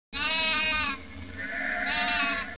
sheep.wav